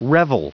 Prononciation du mot revel en anglais (fichier audio)
Prononciation du mot : revel